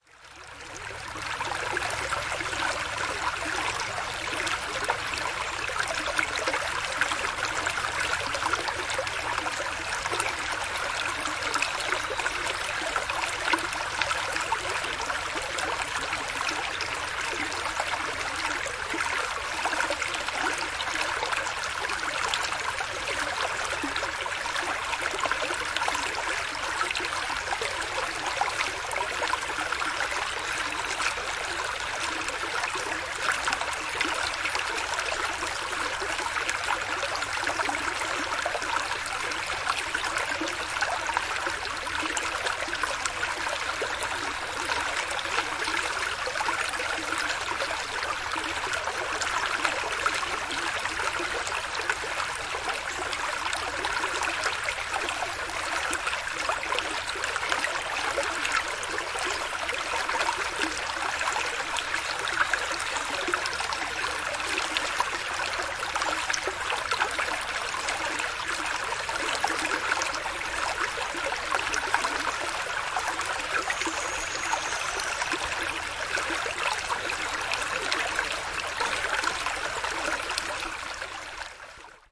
后院
描述：我把我的双声道话筒放在一个大桶的两边，让它们保持大约90分钟。
周围有很多鸟和各种摩擦声，还有一些昆虫的嗡嗡声，以及一些人类的声音，飞机，汽车等。用Sound Professional双耳话筒录入Zoom H4.
标签： 氛围 环境 双耳 汽车 农场 现场记录 森林 地理标记 俄亥俄州 平面 安静 放松
声道立体声